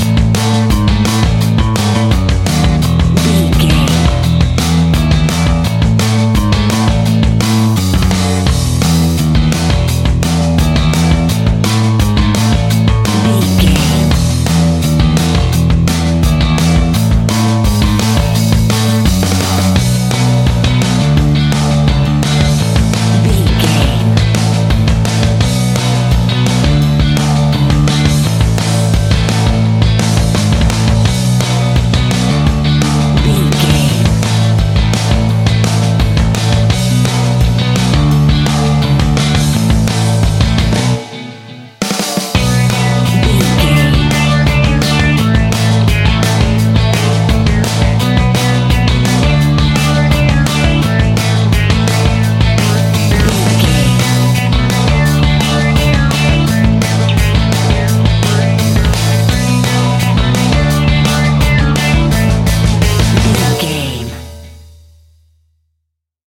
Mixolydian
fun
energetic
uplifting
cheesy
instrumentals
upbeat
rocking
groovy
guitars
bass
drums
piano
organ